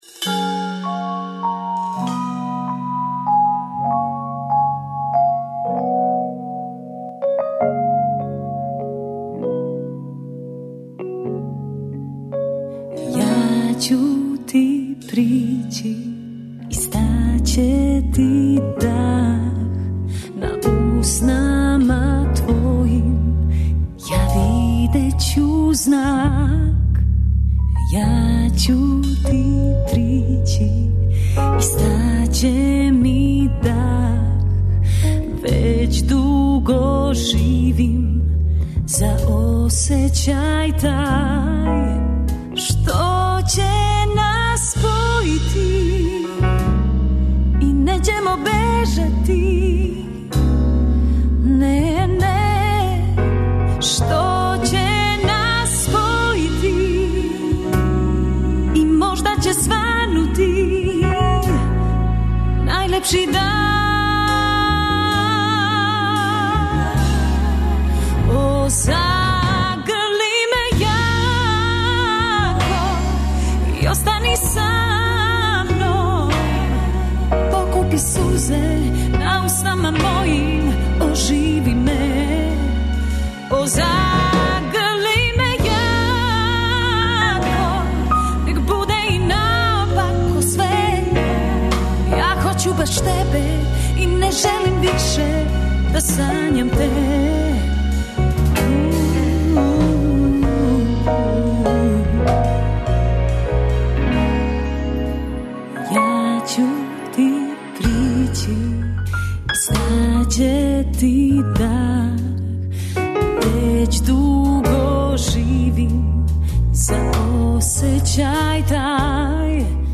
Гости су били и Рубикон квартет који ће у оквиру циклуса "Великани музичке сцене" наступити 5. фебруара у Великој сали Коларчеве задужбине.
Емисија из домена популарне културе.